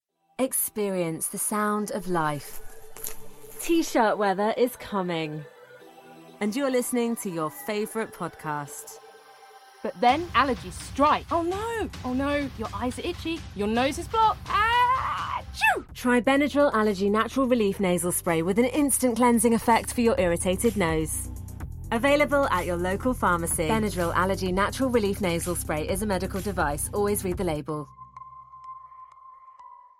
Benadryl using 3D dynamic audio. The campaign used 3D audio technology, as well as date and time, weather and location to find the real time pollen data in the listener’s area. This data enabled us to recommend the correct type of relief for the listener, directing them to their local Tesco store.